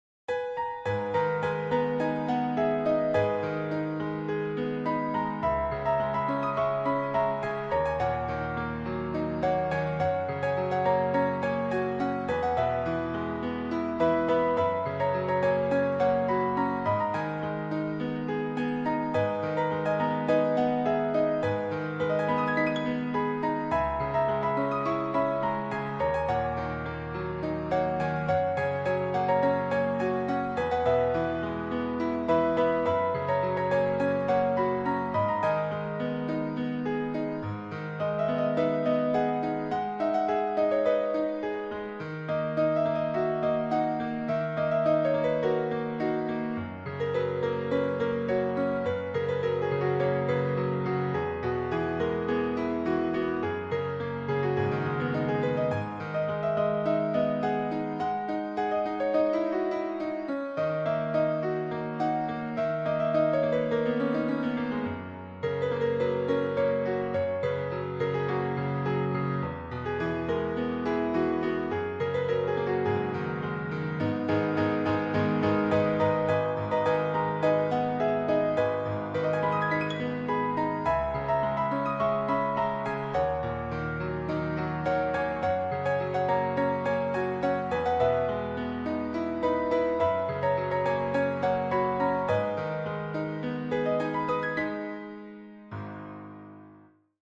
Mahnının melodiyası zərif və ürəyə toxunandır.